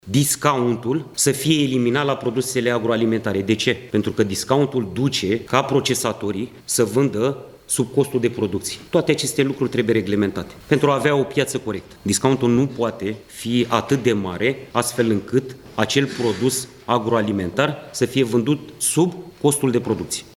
Ministrul Agriculturii vrea să fie limitate reducerile practicate la alimente, pentru ca producătorii să nu mai înregistreze pierderi. Într-o conferință de presă, Florin Barbu a vorbit și despre anumite practici neloiale ale marilor magazine.